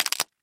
Звуки запикивания мата
запикивание короткое спокойное